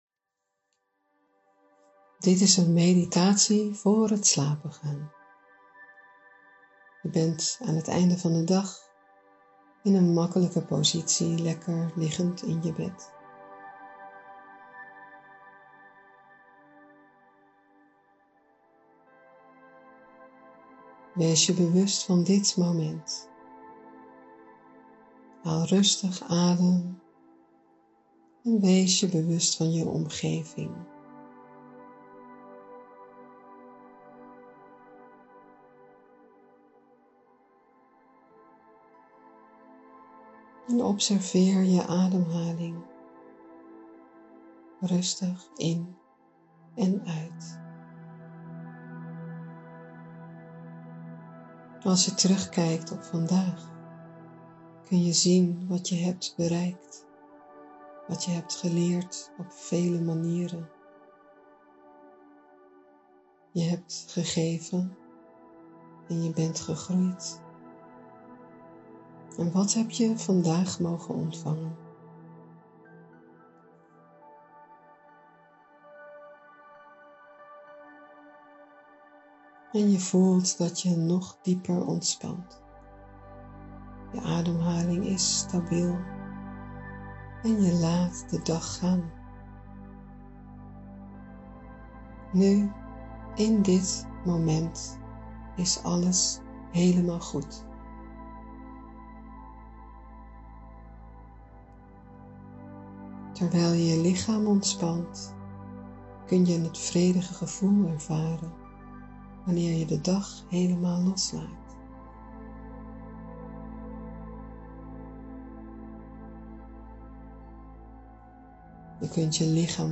Meditatie Voor het slapen gaan download pagina - Centrum Close2Nature
Meditatie-voor-het-slapen.mp3